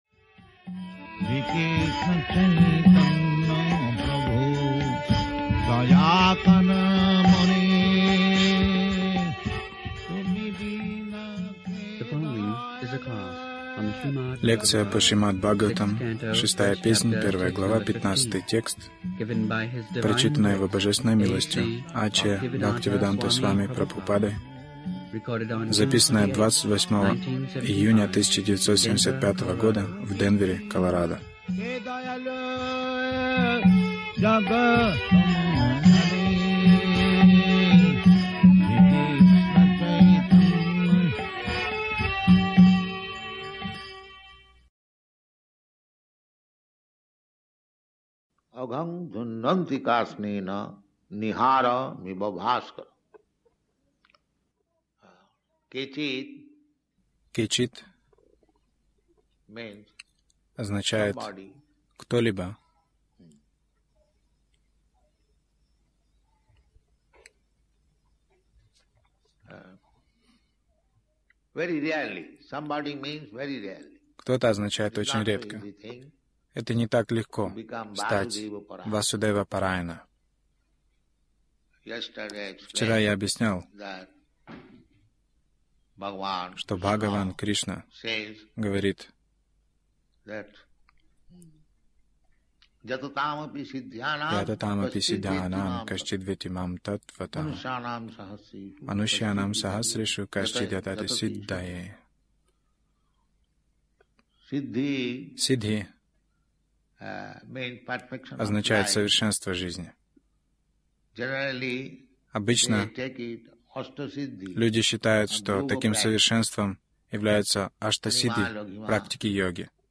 Милость Прабхупады Аудиолекции и книги 28.06.1975 Шримад Бхагаватам | Денвер ШБ 06.01.15 Загрузка...